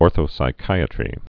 (ôrthō-sĭ-kīə-trē, -sī-)